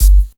kick 03.wav